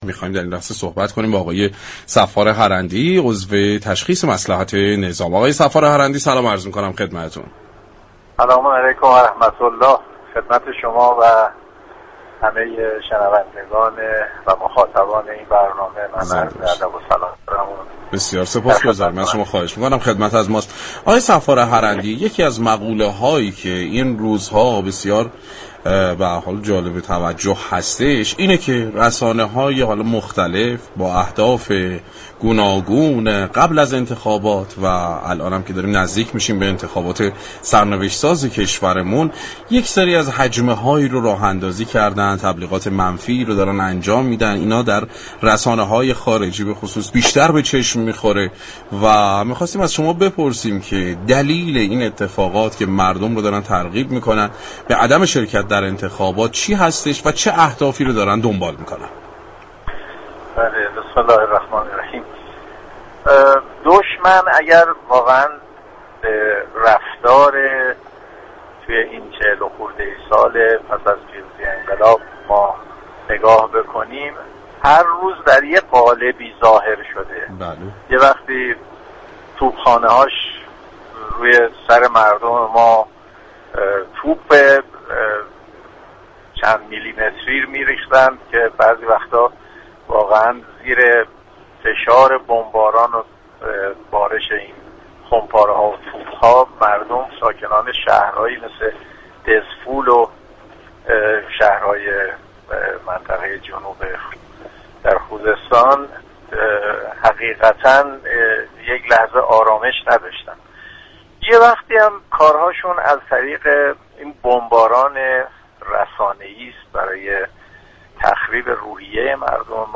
به گزارش پایگاه اطلاع رسانی رادیو تهران، محمدحسین صفار هرندی عضو مجمع تشخیص مصلحت نظام در گفتگو با برنامه سعادت آباد رادیو تهران با اشاره به هجمه‌ها و تبلیغات منفی در رسانه های معاند و هدف آنها از این تبلیغات گفت: دشمن پس از انقلاب با رفتارهای متفاوتی ظاهر شده‌ است.